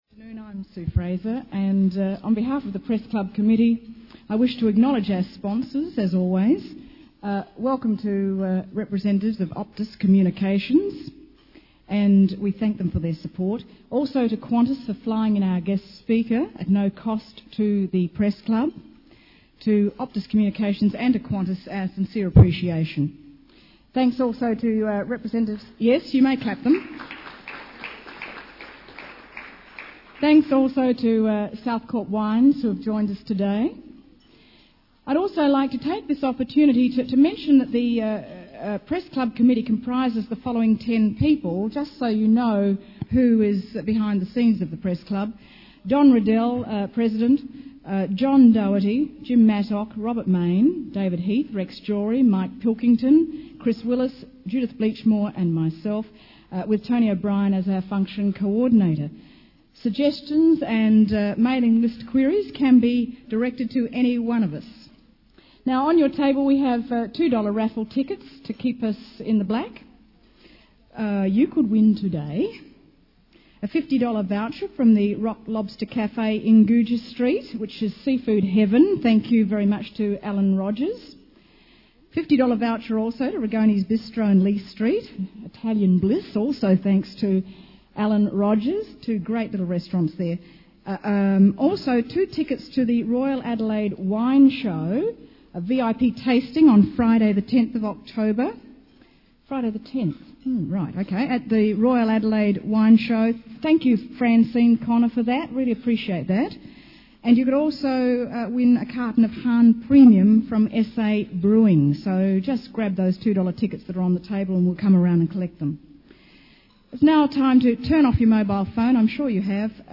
International Editor, The Australian
Event Category: Luncheons